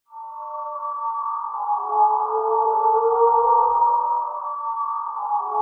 HAUNTING.wav